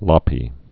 (lŏpē)